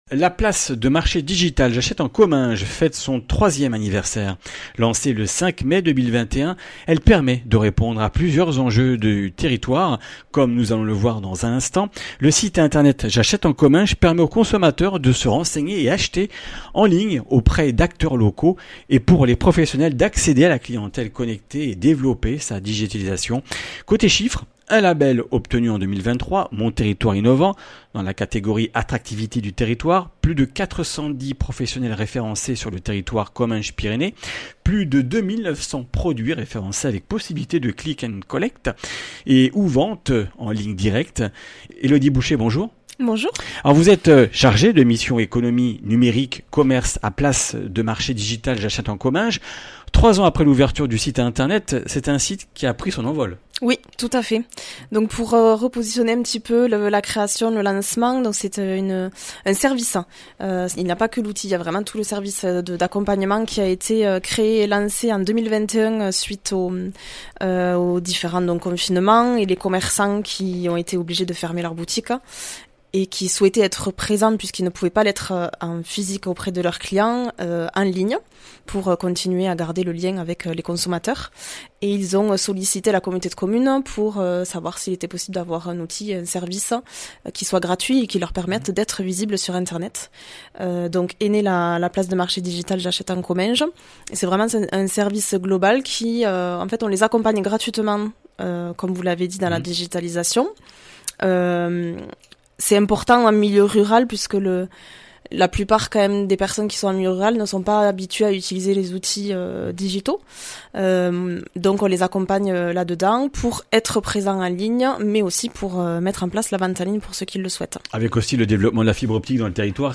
Accueil \ Emissions \ Information \ Régionale \ Le grand entretien \ La place de marché digitale “J’achète en Comminges” fête son 3ème anniversaire !